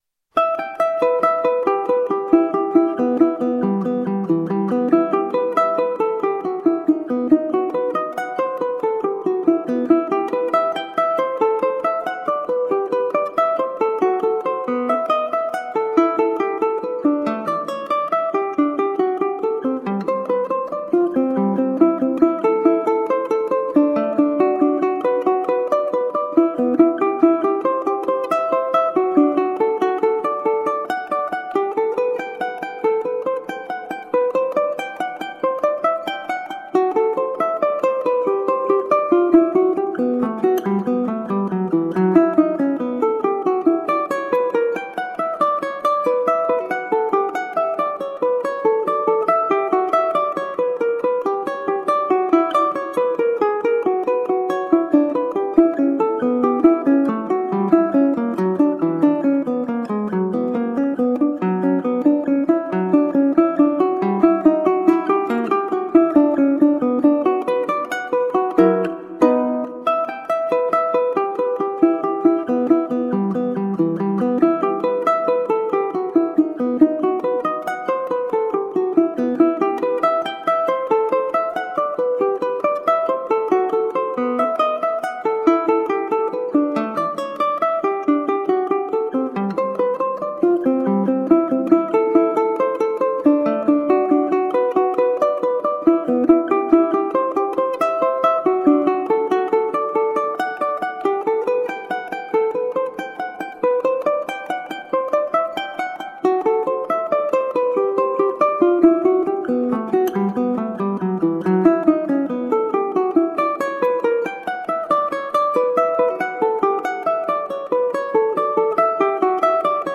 Colorful classical guitar.